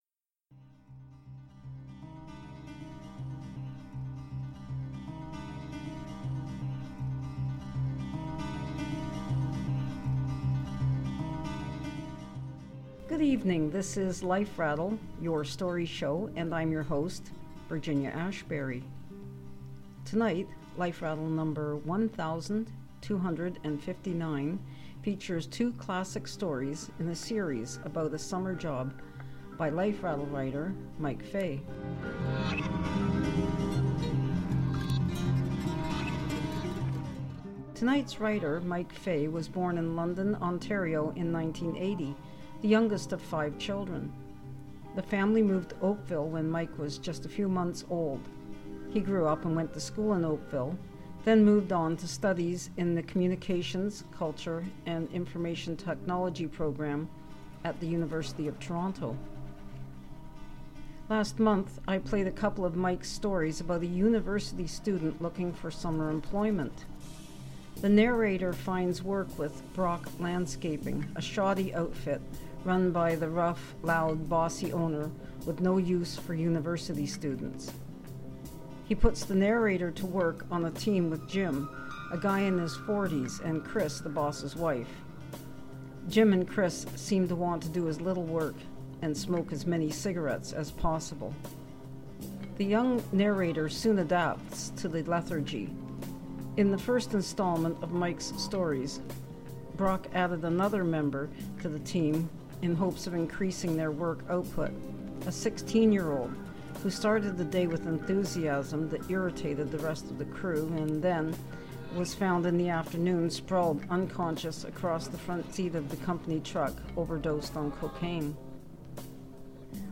Please note: tonight’s stories, written for and about adults, include language that some listeners may find offensive.